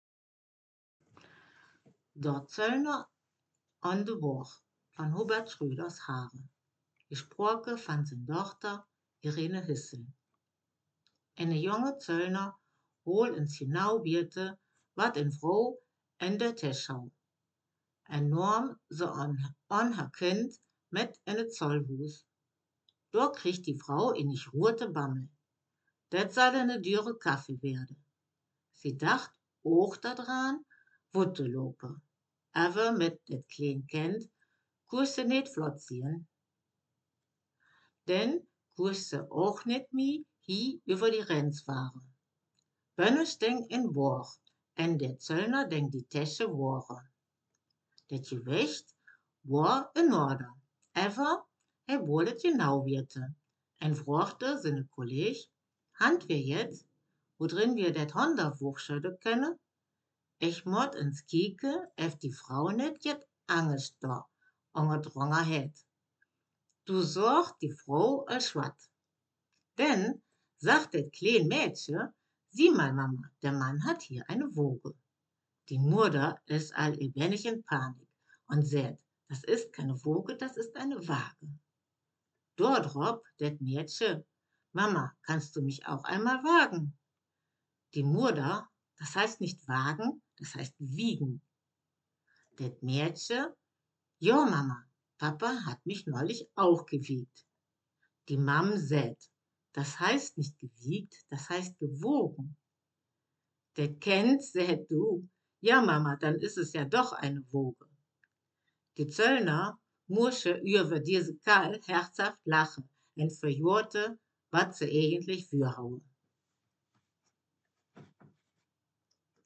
Text Mundart
Gangelter-Waldfeuchter-Platt
Geschichte